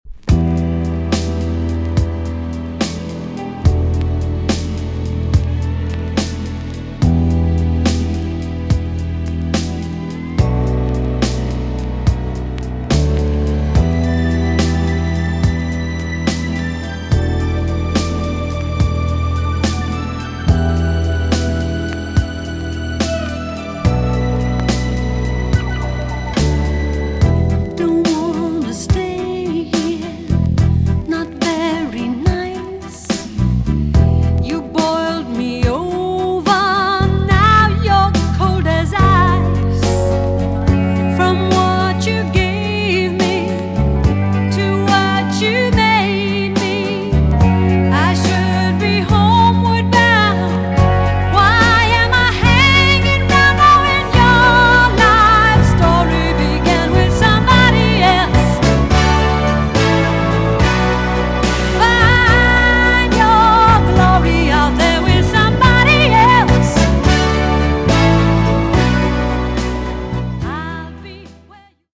EX- 音はキレイです。
1980 , WICKED JAMAICAN SOUL TUNE!!